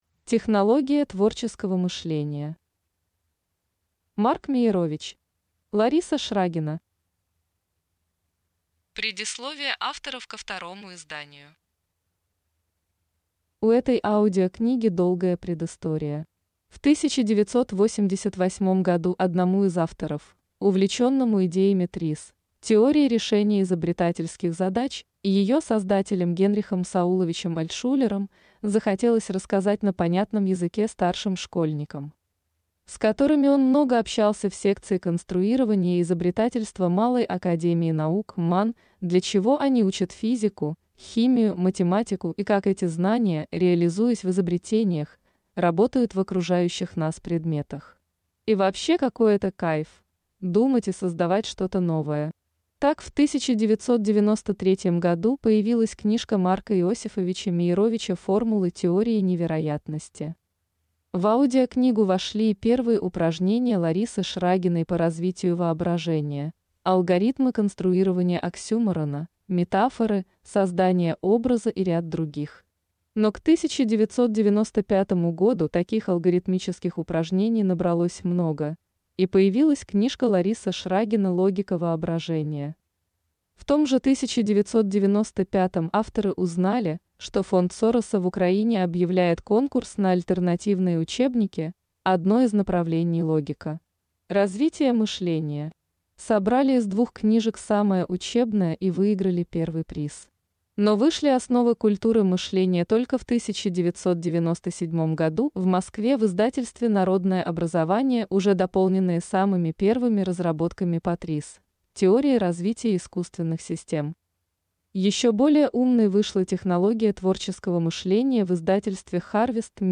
Аудиокнига Технология творческого мышления | Библиотека аудиокниг